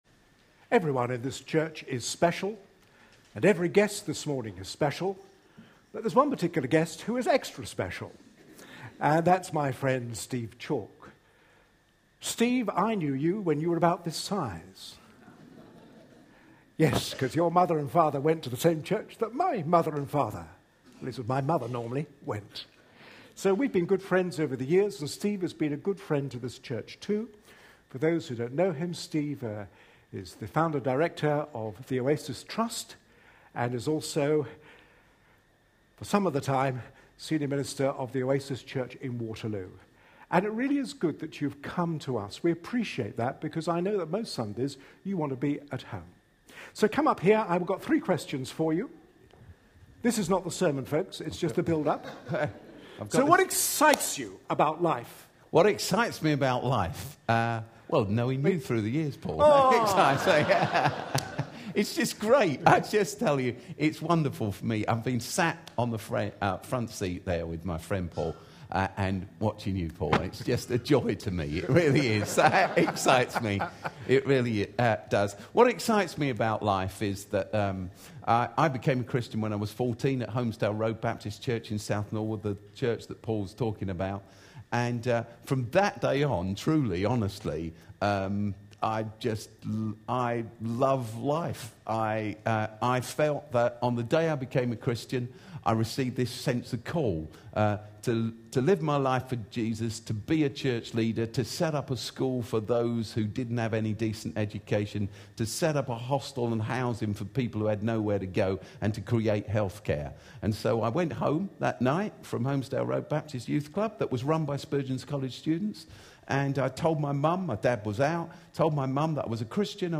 Steve Chalke Interview.
A sermon preached on 19th May, 2013, as part of our 108th Church Anniversary Services series.